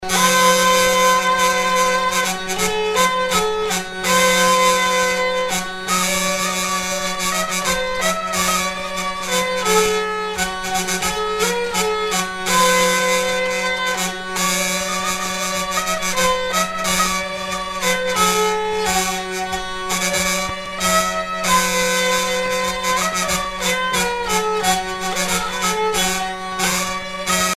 Chants brefs - Conscription
Pièce musicale éditée